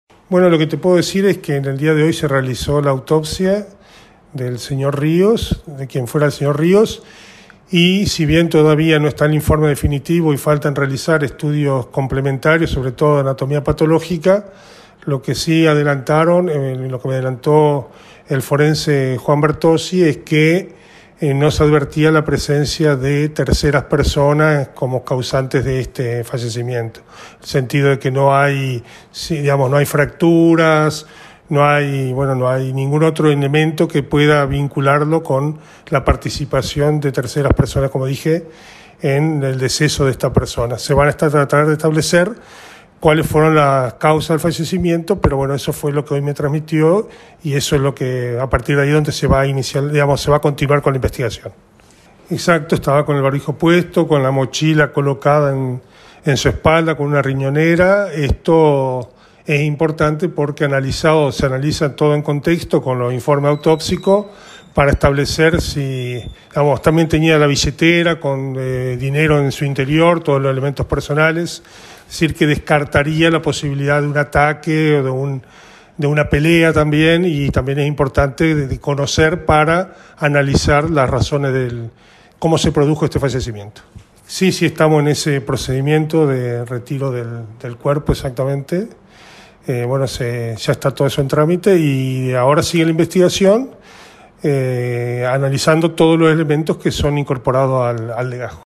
El funcionario judicial dialogó con AM 980 Radio Victoria y dijo que “por el primer informe proporcionado, se puede indicar que no habría participado otra persona de la muerte”.